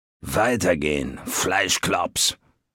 Fallout 3: Audiodialoge
Malegenericghoul_dialoguemsmini_hello_000c9ccb.ogg